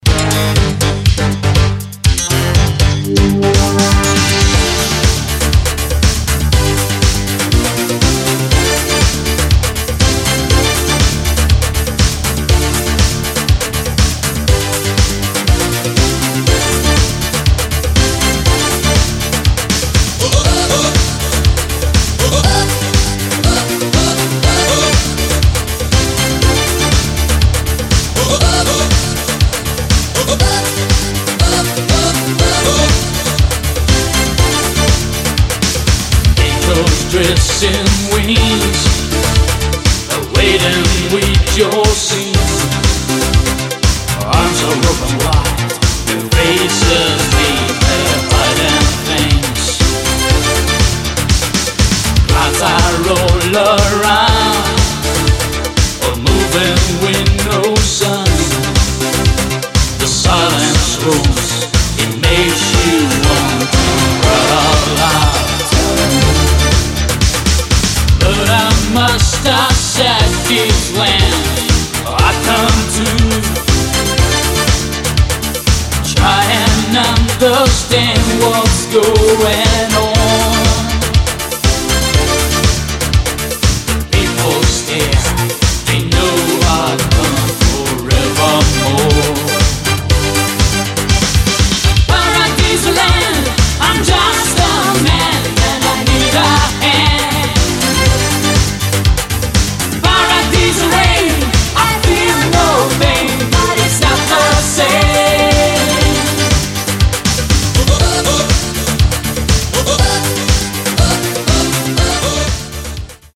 A pwoerful track with heavy synths and fantastic vocals